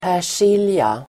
Ladda ner uttalet
persilja substantiv, parsley Uttal: [pär_s'il:ja (el. ²p'är_s:il:ja)] Böjningar: persiljan Definition: en grön kryddväxt av släktet Petroselinum sativum Idiom: prata persilja ("prata strunt") (talk nonsense) Sammansättningar: persilje|kvist (sprig of parsley)